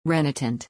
PRONUNCIATION:
(REN-uh-tuhnt)